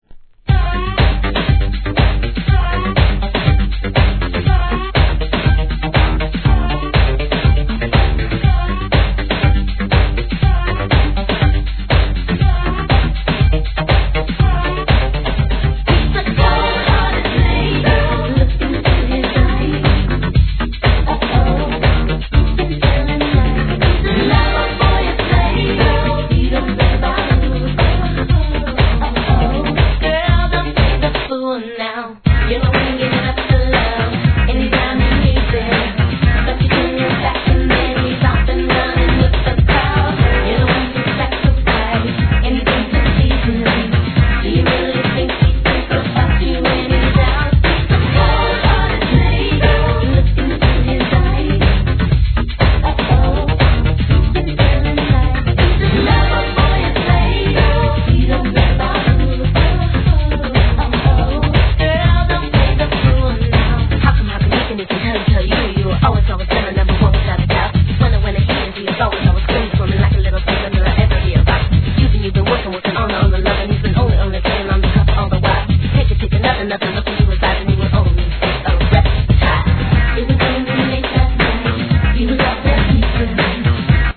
HIP HOP/R&B
跳ねBEATにRAPも披露!!